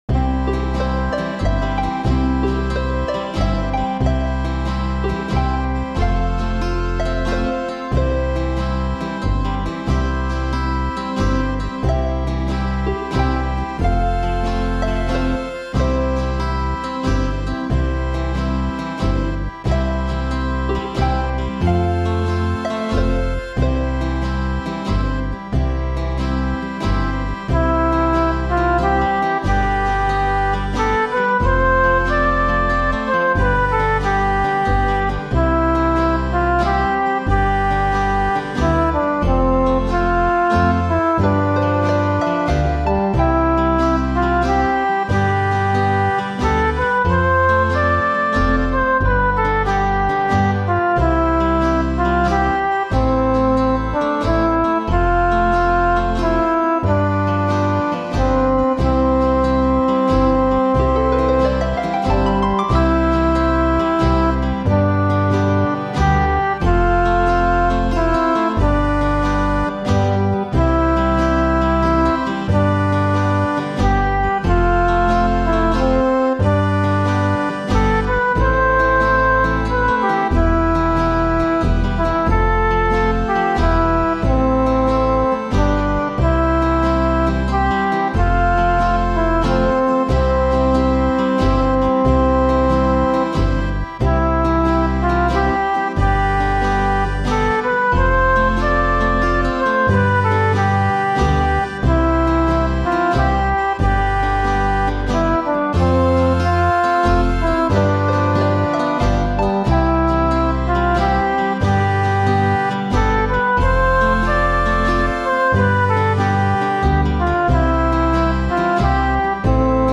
A sweet gentle song